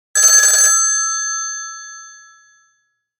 Classic Telephone Ring - Old Rotary Phone Sound Effect
Description: Classic telephone ring short sound. Old rotary phone from the 1960s and 1970s rings once shortly. Notification sound, ringtone, or message alert.
Classic-telephone-ring-short-sound.mp3